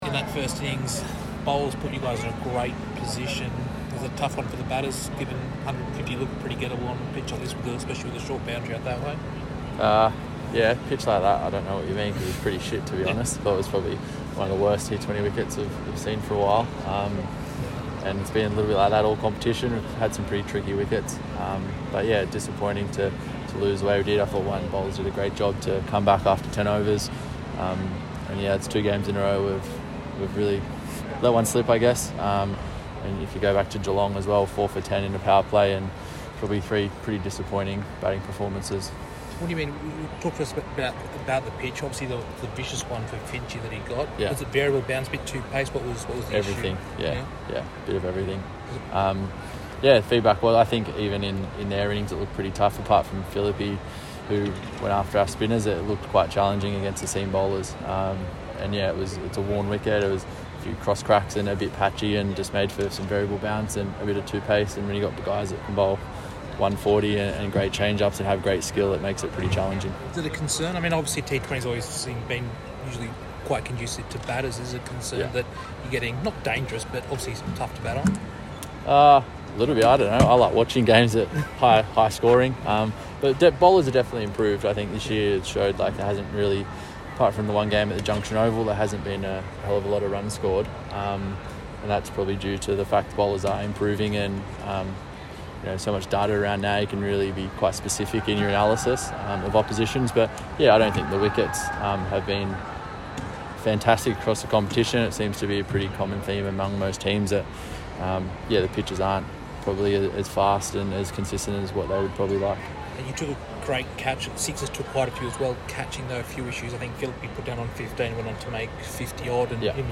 Renegades captain Nic Maddinson spoke to media following the 34 run loss to the Sixers.